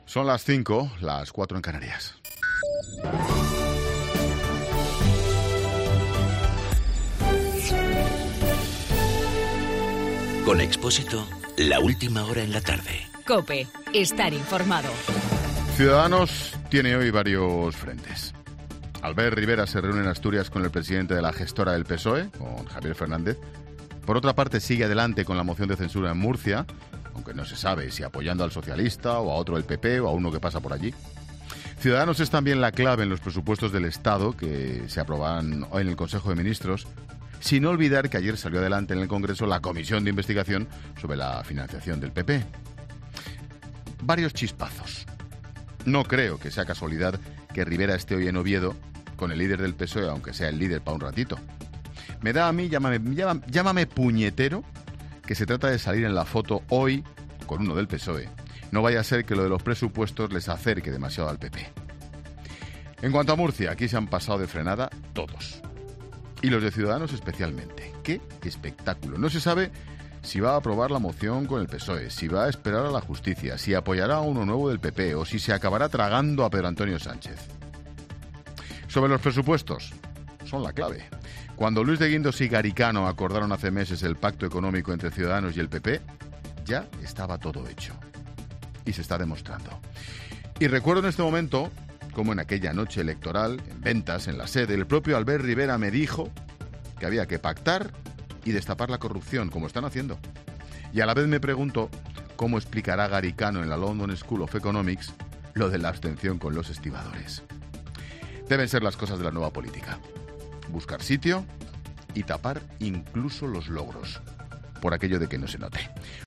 AUDIO: Monólogo 17 h.